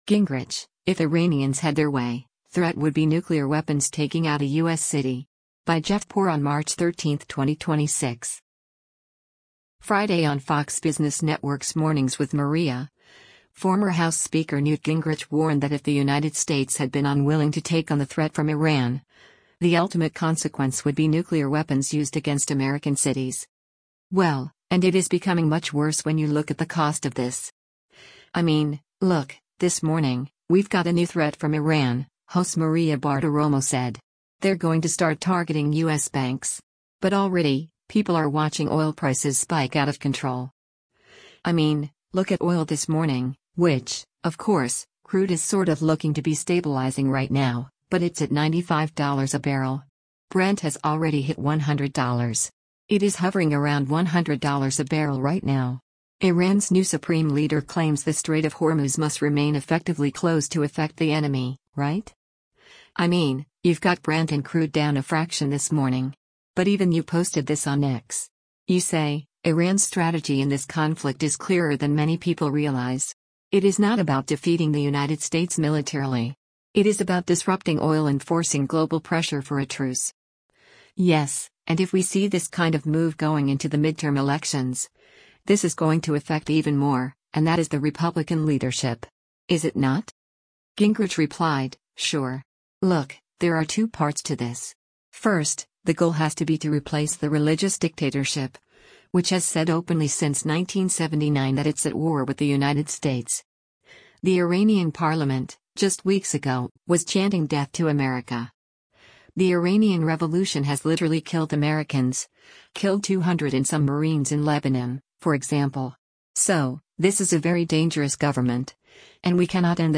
Friday on Fox Business Network’s “Mornings with Maria,” former House Speaker Newt Gingrich warned that if the United States had been unwilling to take on the threat from Iran, the ultimate consequence would be nuclear weapons used against American cities.